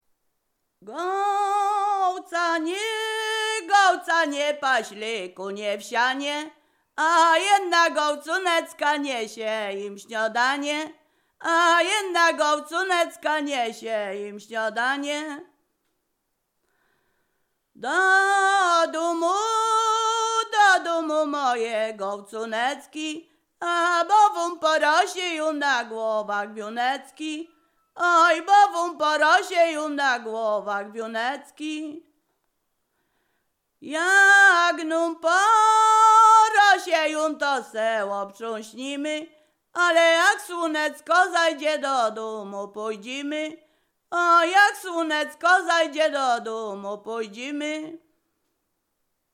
Ziemia Radomska
Przyśpiewki
miłosne weselne wesele przyśpiewki